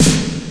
Free MP3 Roland TR606 - Snare drums 2
Snare - Roland TR 27